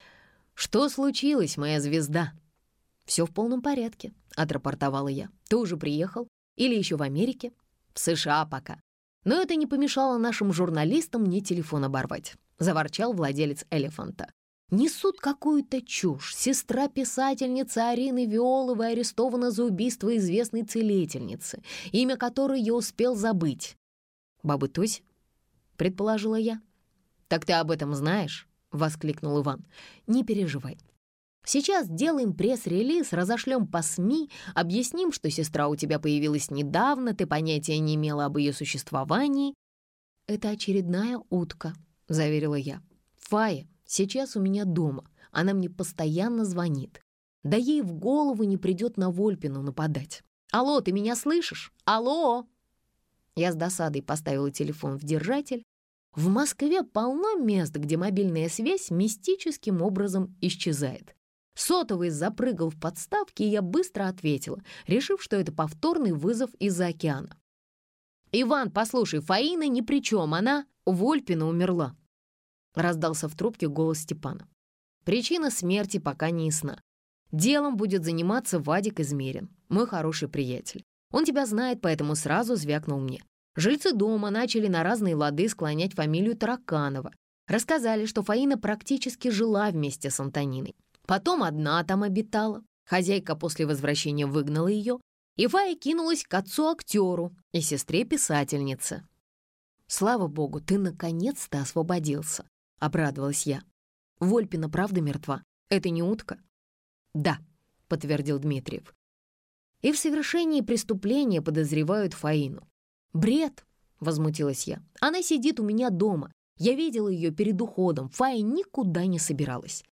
Аудиокнига «Инкогнито с Бродвея» в интернет-магазине КнигоПоиск ✅ в аудиоформате ✅ Скачать Инкогнито с Бродвея в mp3 или слушать онлайн